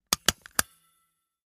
fo_stapler_04_hpx
Papers are stapled together. Paper, Staple